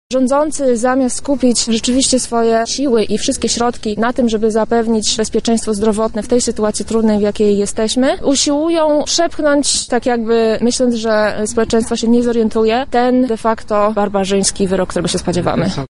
„Wyrok na kobiety” – tak protestujący nazywają propozycję zaostrzenia ustawy antyaborcyjnej. Pod pomnikiem Marii Curie-Skłodowskiej odbył się symboliczny happening.